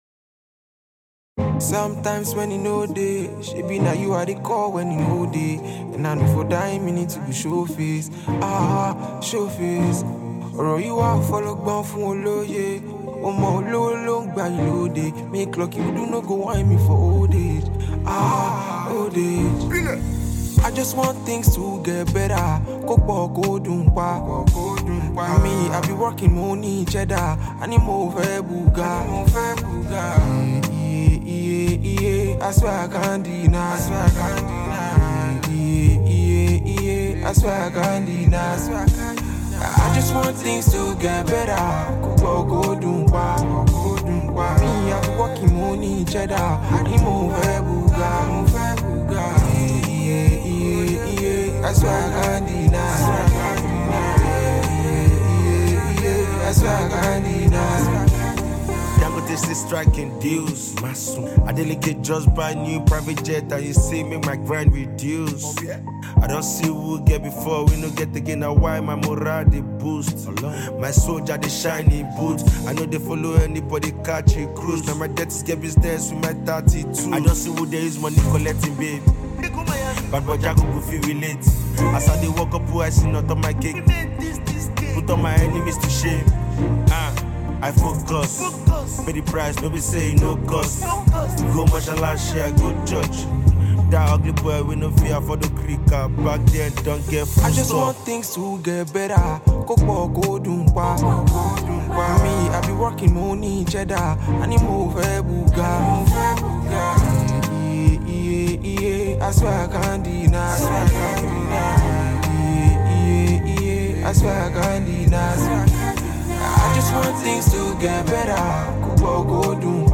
soulful singer